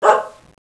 dog_terrier.wav